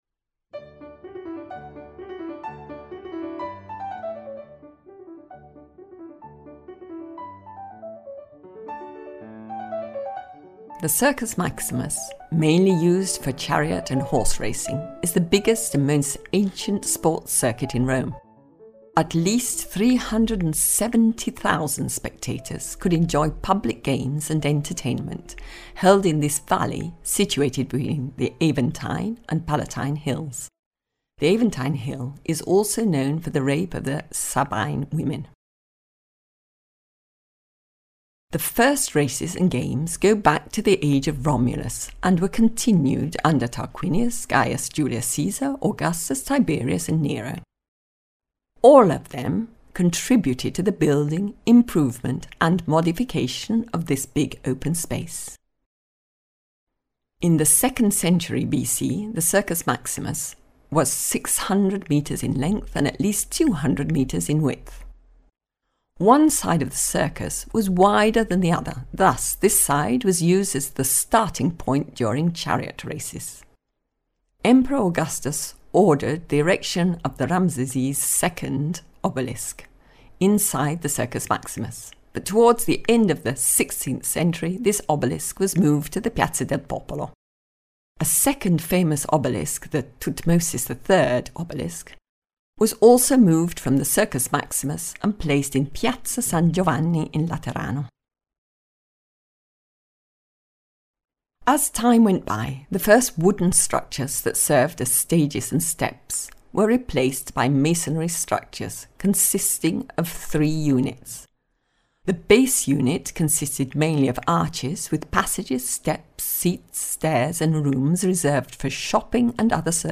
Audio Guide Rome - The Circus Maximus - Audiocittà